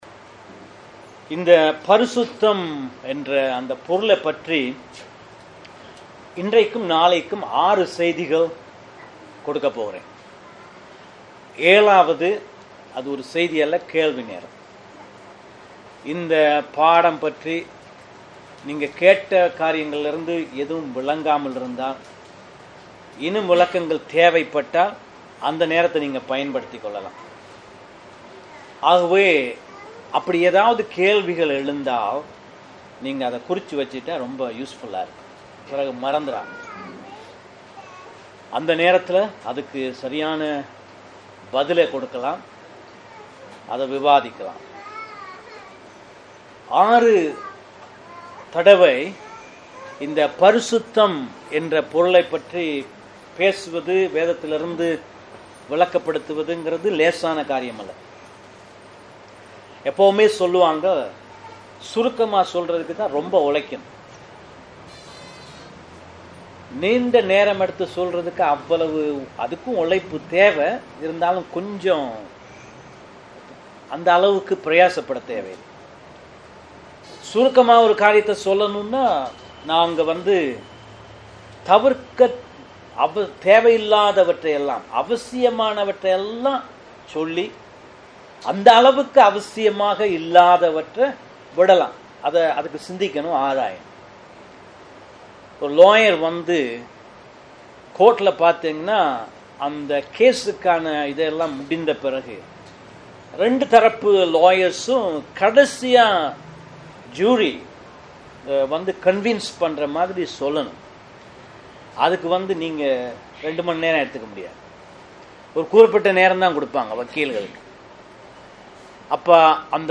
கீழ்வரும் பிரசங்கங்களை ஒலி (Audio) வடிவில் கேட்கலாம் & பதிவிறக்கம் (Download) செய்துக்கொள்ளலாம்.